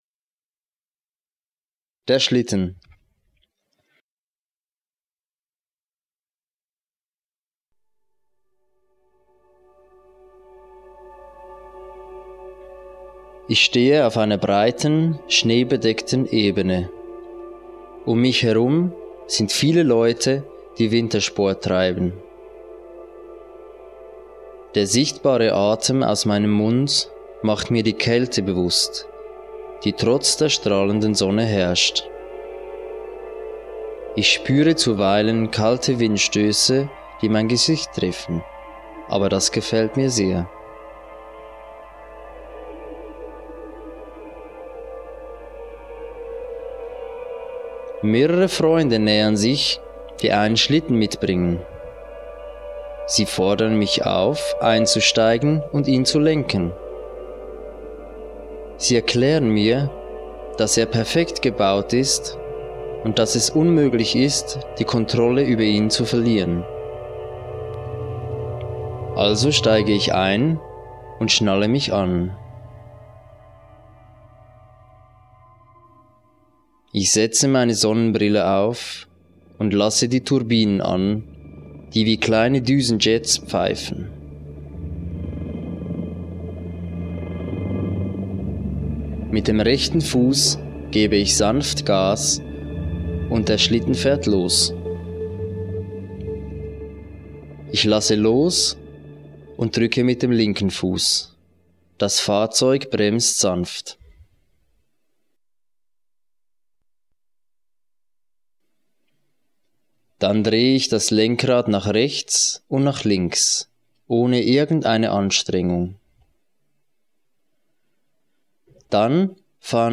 Geleitete Erfahrung Ich stehe auf einer breiten, schneebedeckten Ebene.